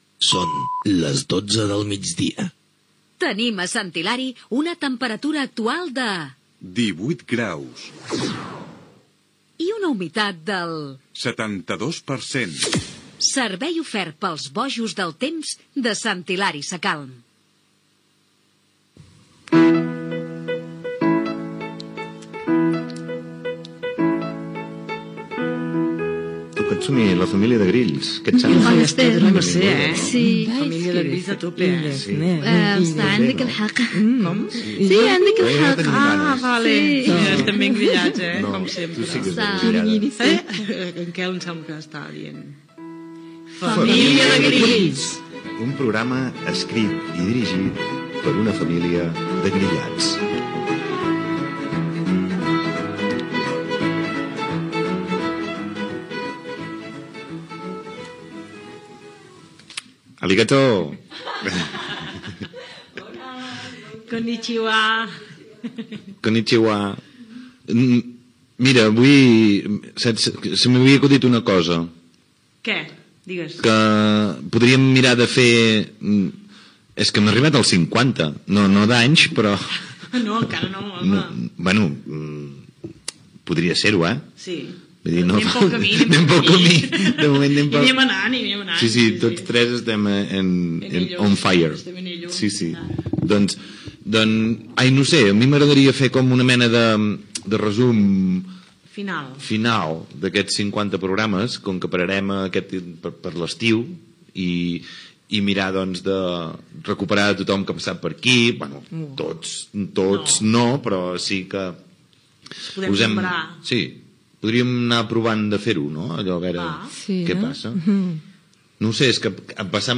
Hora, temperatura, careta del programa, inici del programa 50 recordant com van ser els seus inicis.
Entreteniment